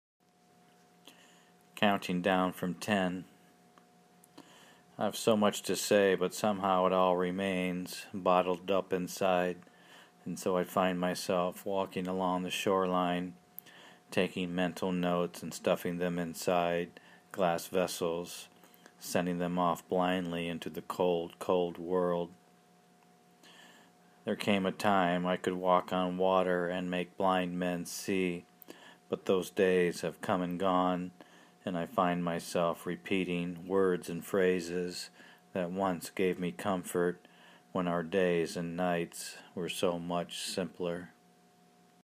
Love the reading too.